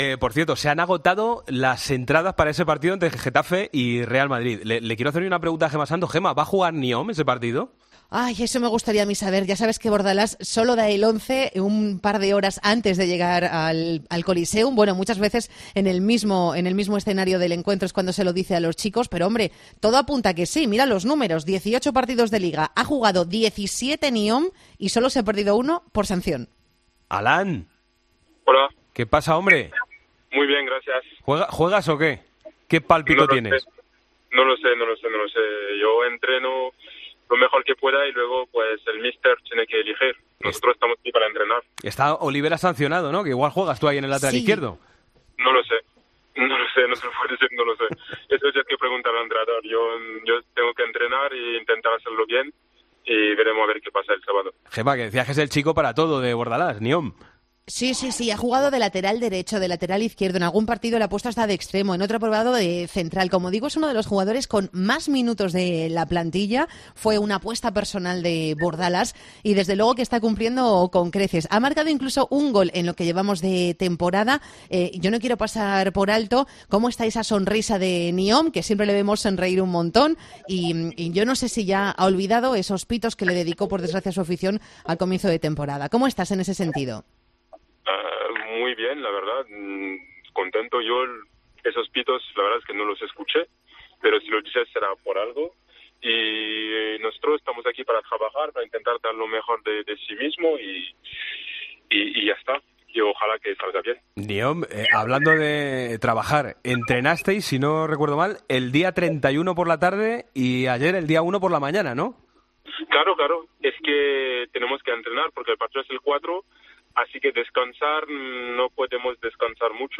El jugador del Getafe ha pasado por los micrófonos de Deportes COPE a dos días de enfrentarse al Real Madrid.